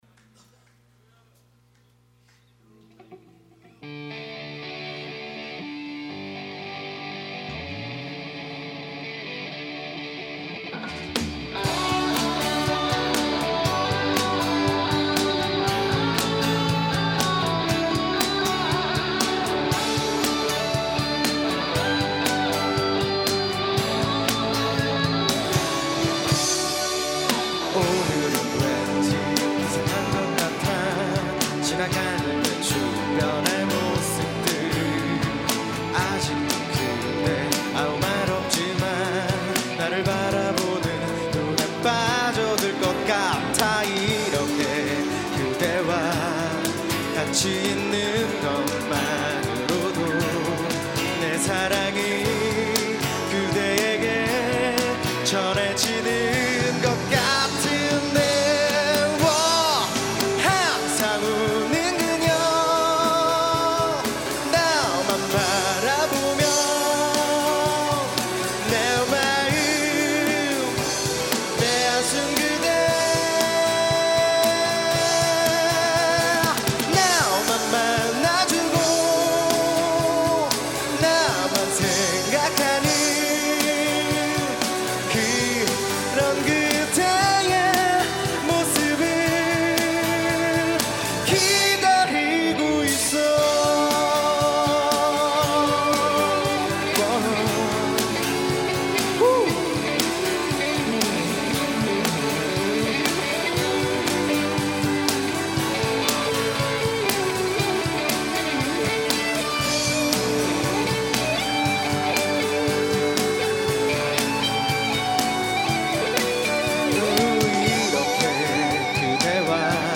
2005년 신입생 환영공연
홍익대학교 신축강당
어쿠스틱기타
일렉트릭기타
드럼